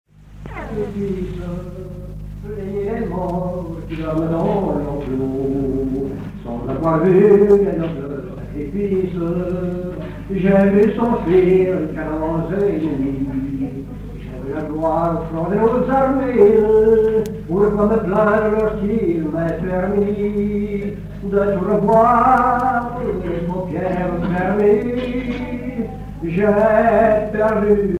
Genre strophique
Chansons d'un banquet
Pièce musicale inédite